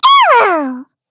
Taily-Vox_Attack4_kr.wav